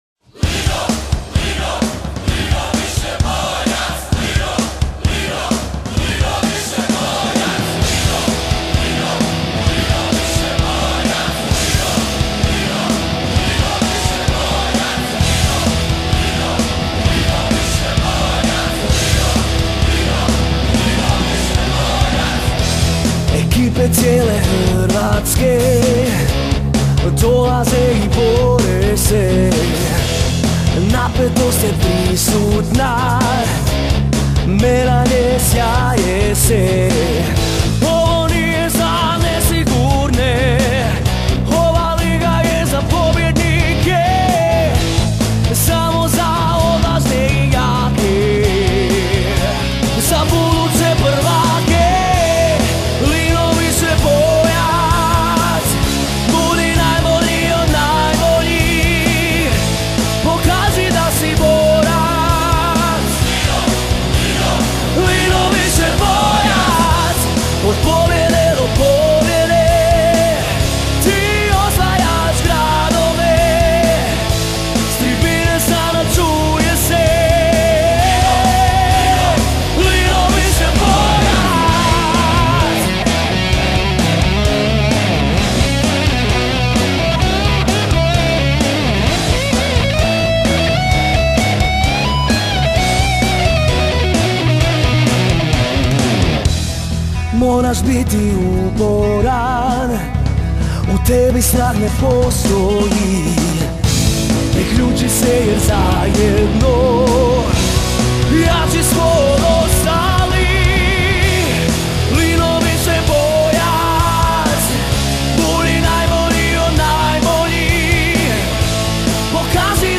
himna